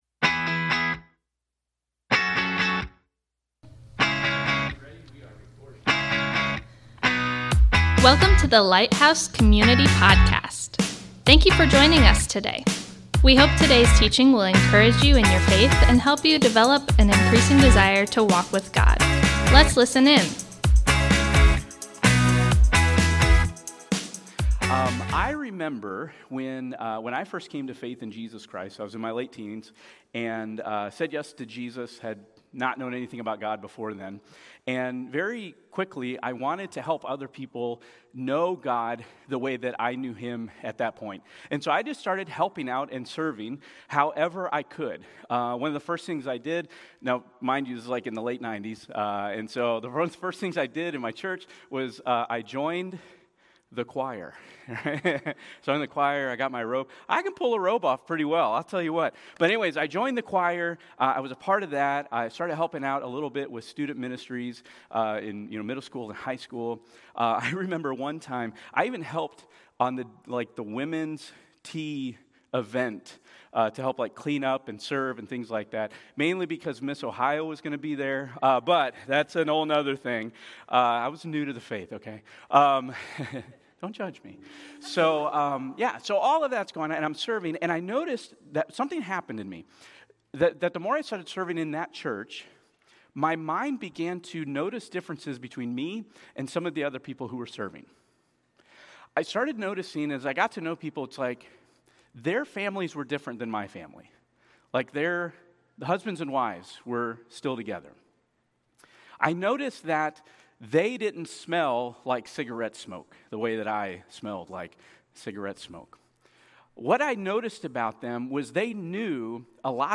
Thank you for joining us today as we come together to worship! Today, we continue our teaching series: HELLO MY NAME IS...In this series, we are looking at Scripture to answer the question: What is the purpose of the Church? Today, we’re in Luke 7:20-23 where we see that when we are made right with God by grace through faith we join God in his rescue mission of humanity!